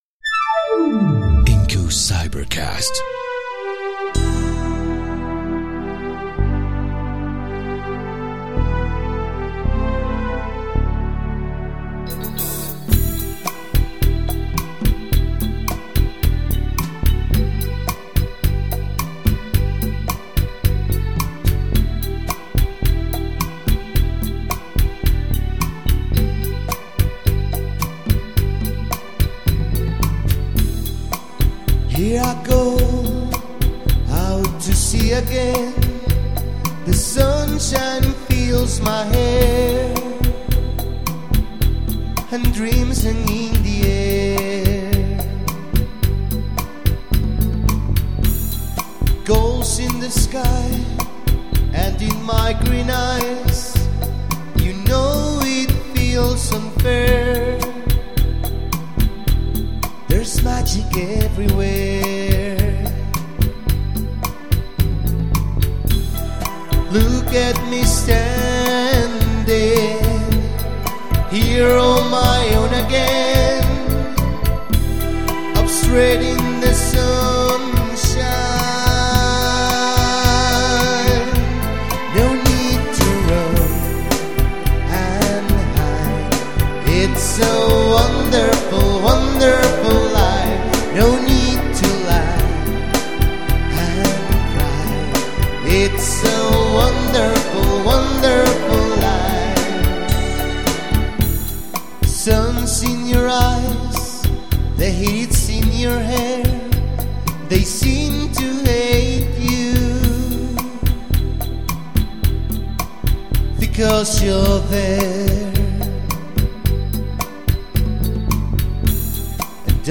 VOZ Y COROS